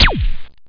ROCH_GUN.mp3